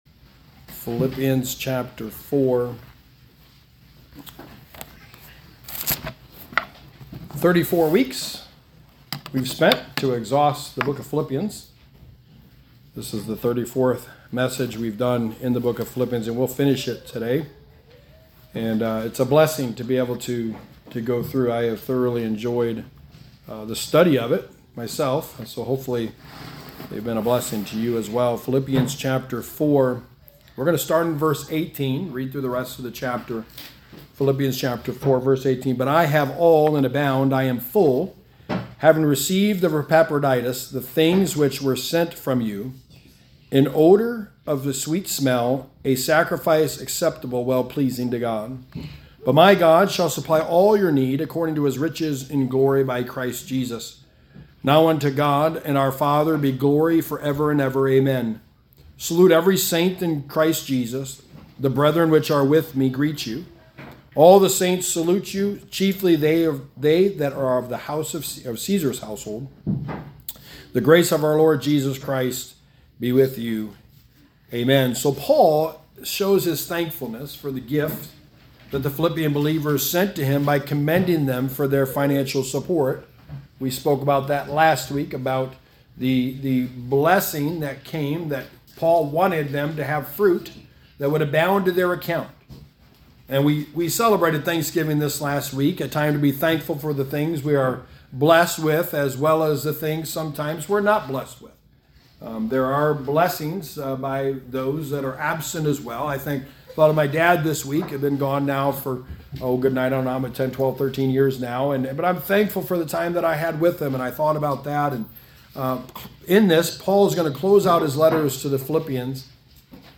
Passage: Philipianns 4:18-23 Service Type: Sunday Morning